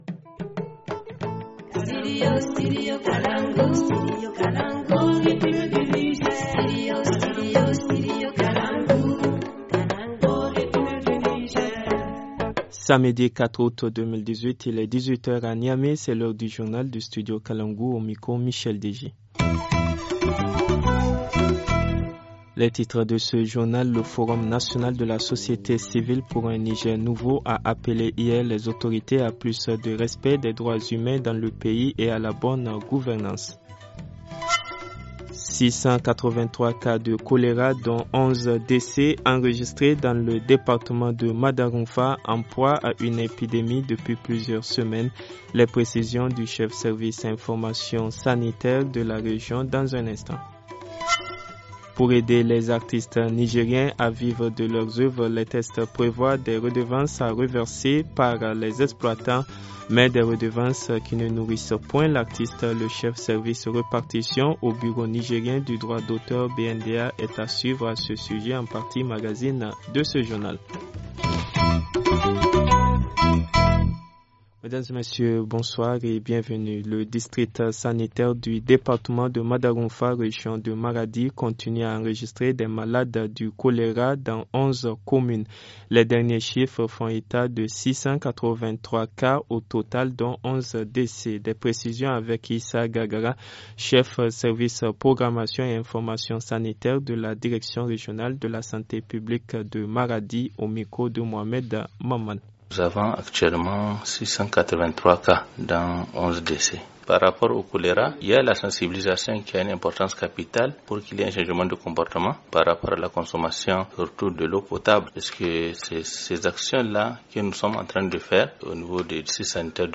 Le journal du 04 août 2018 - Studio Kalangou - Au rythme du Niger